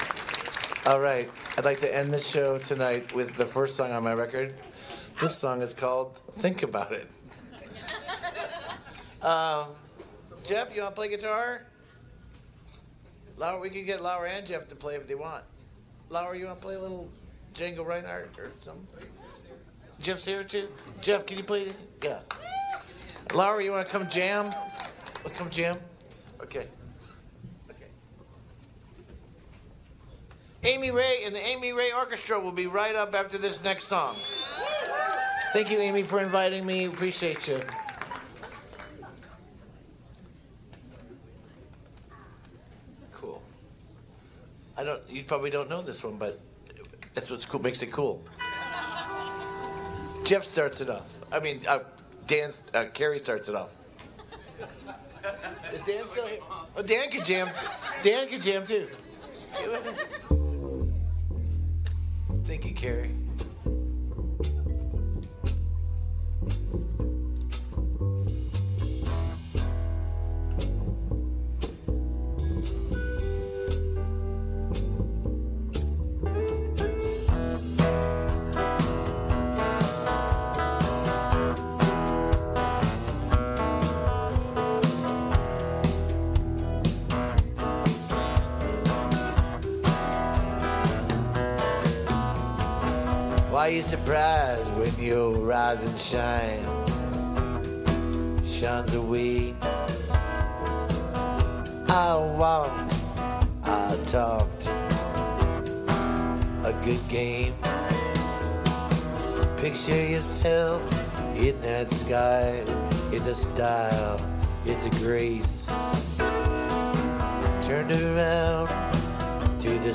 (captured from the live stream)